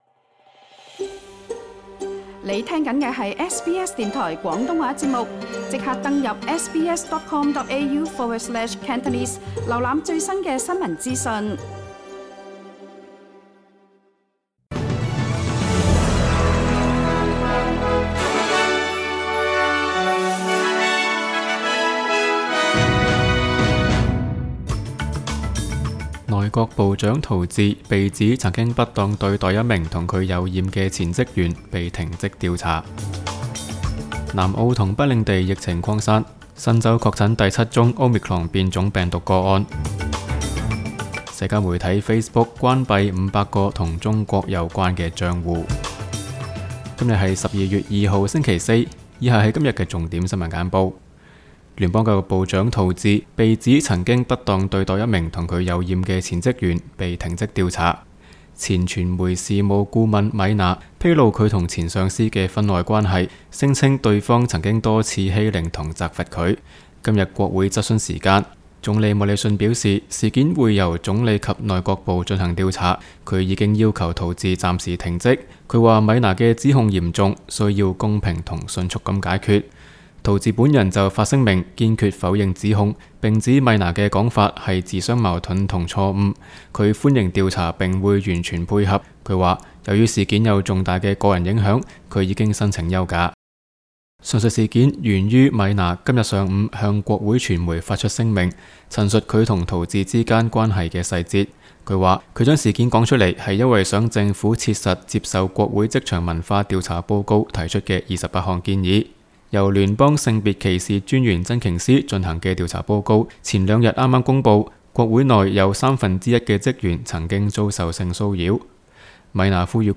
SBS 新闻简报（12月2日）
SBS 廣東話節目新聞簡報 Source: SBS Cantonese